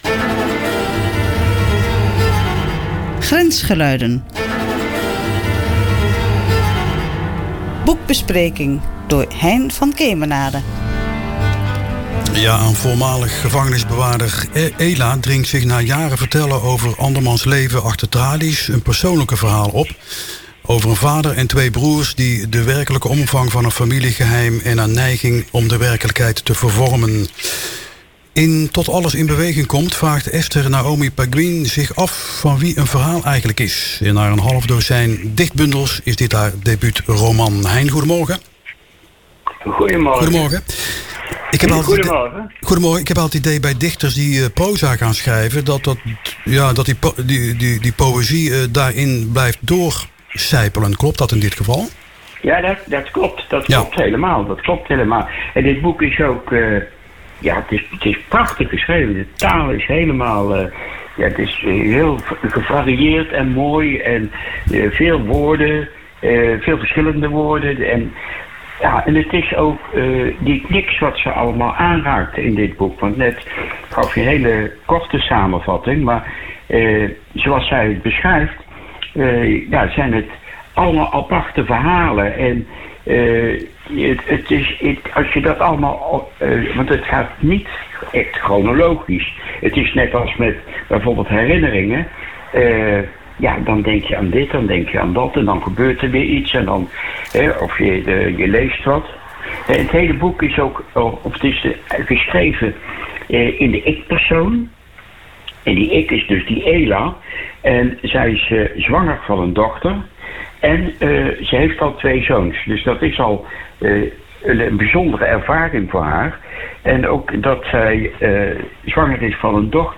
Klik op de afbeelding of op het speakertje om een kort interview op  BredaNu te horen, steeds over een boek.